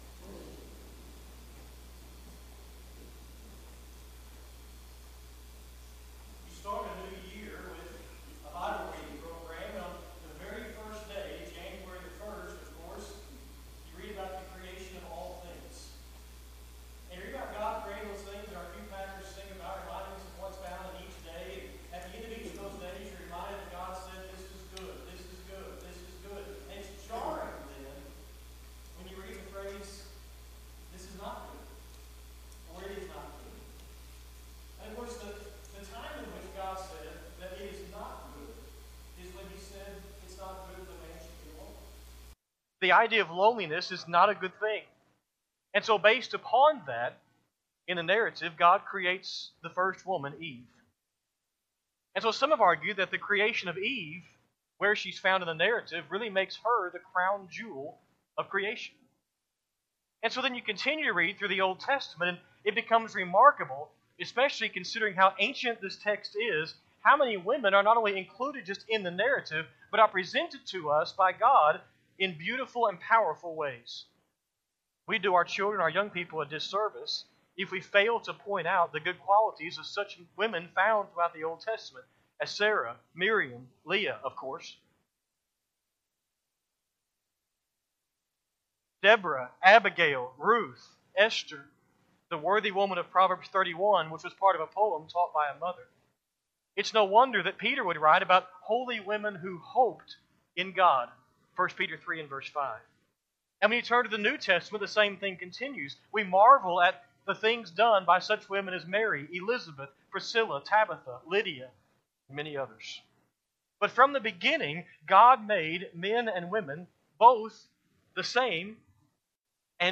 Sunday-PM-Sermon-11-9-25-Audio.mp3